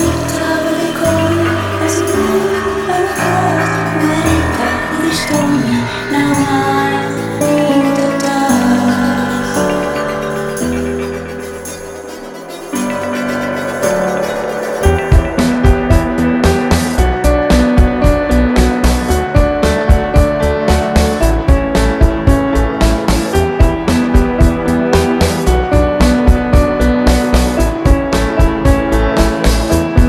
Cordes heurtées des guitares électriques
ensemble emporté par des orgues gothiques